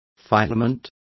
Complete with pronunciation of the translation of filament.